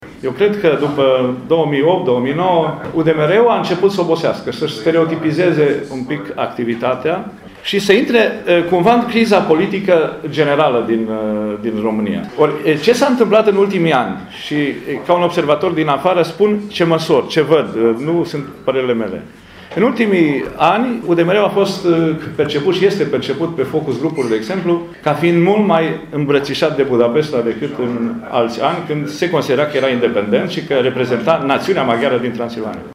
Președintele IRES, Vasile Dâncu, a declarat astăzi, la Tîrgu-Mureș, în cadrul dezbaterii despre protecția minorităților, că UDMR a intrat și ea în criza politică generală.